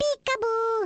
One of Baby Mario's voice clips from the Awards Ceremony in Mario Kart: Double Dash!!